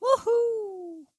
Звуки радости
На этой странице собраны звуки радости — от искреннего смеха до бурных оваций.
Мультяшный звук юху